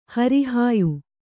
♪ hari hāyu